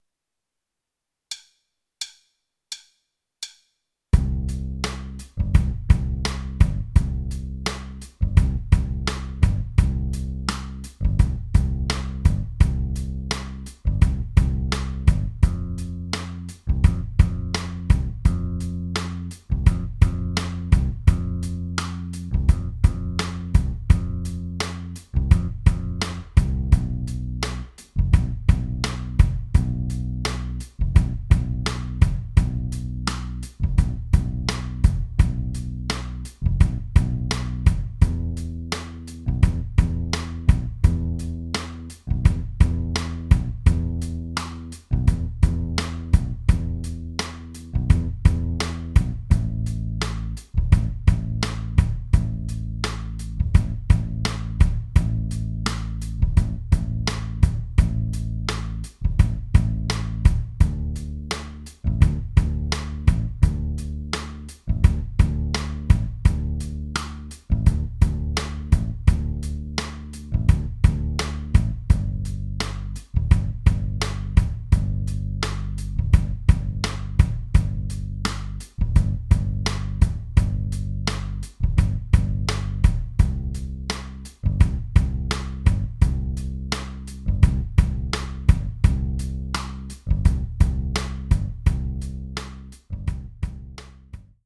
Podkład (kolejność akordów: C, F, Hdim, Em, Am, Dm, G, C):